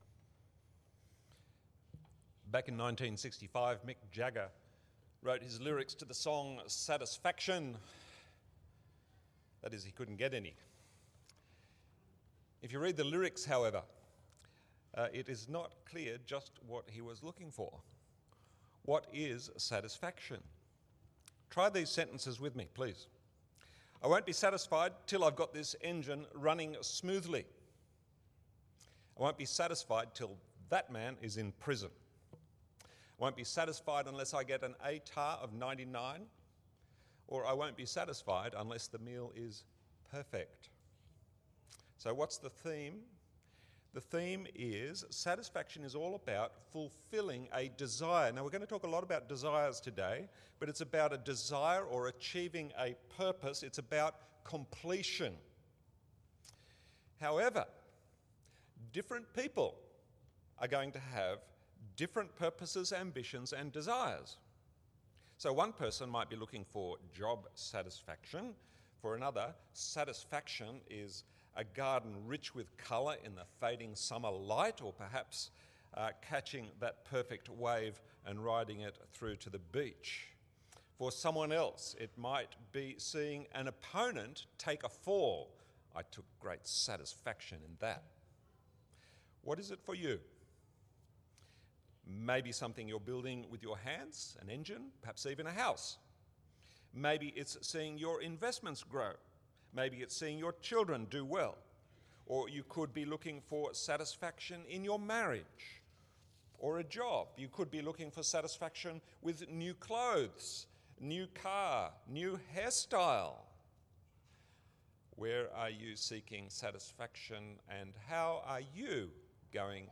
Sunday sermon
from St John’s Anglican Cathedral Parramatta.